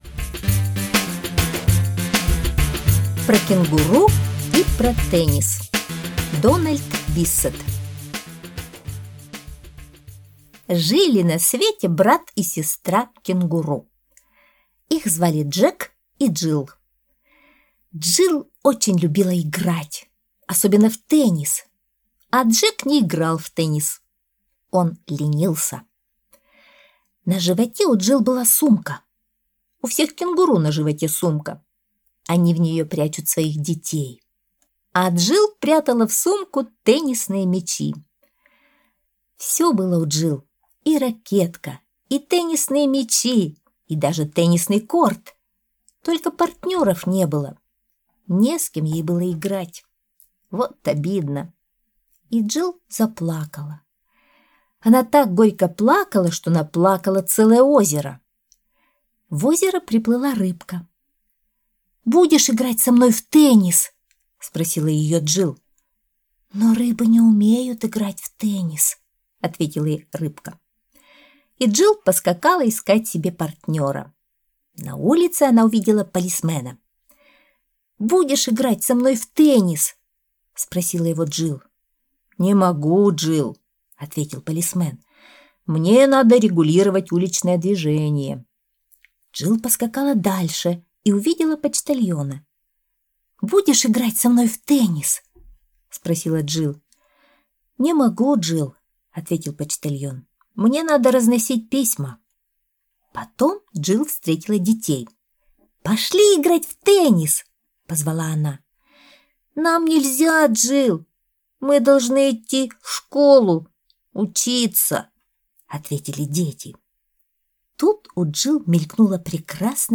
Аудиосказка «Про кенгуру и про теннис»